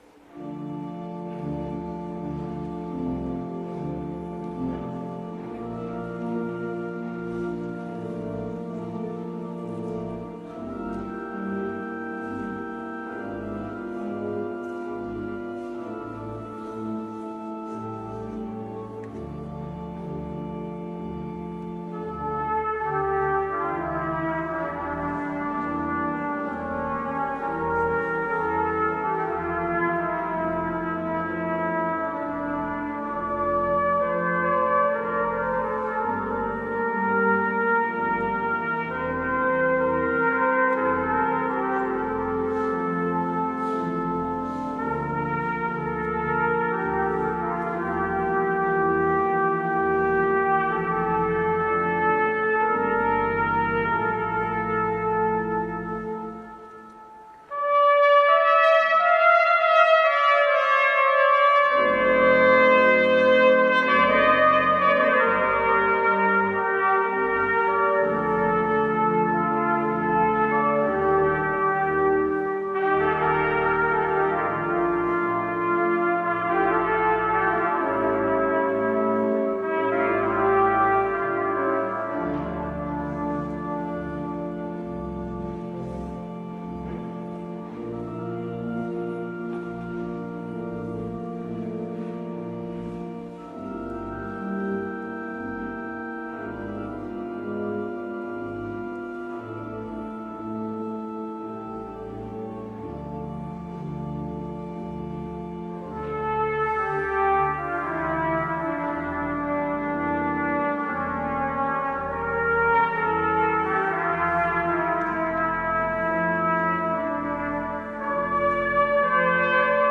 Koncert organowy „Krzyżu Chrystusa” 9 marca 2013
4. Tomaso Albinoni – Adagio g-moll na trąbkę i organy